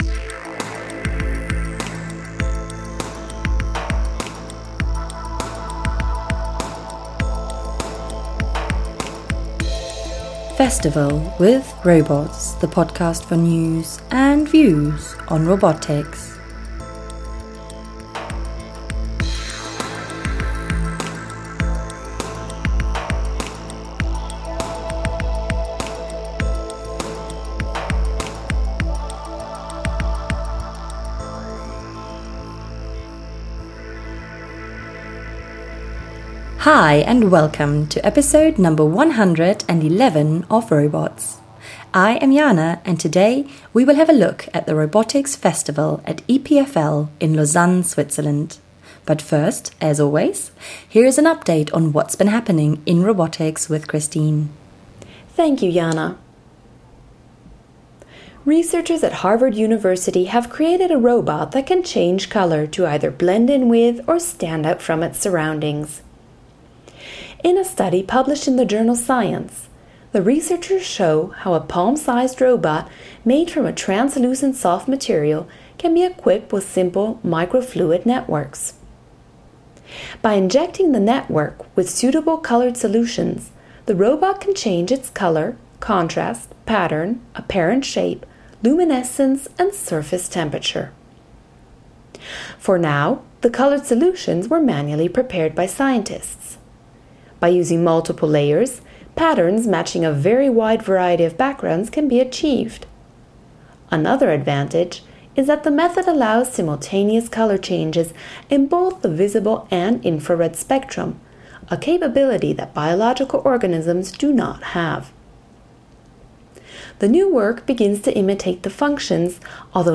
In this episode, we take you to some of the demos:
Thymio Podcast team The ROBOTS Podcast brings you the latest news and views in robotics through its bi-weekly interviews with leaders in the field.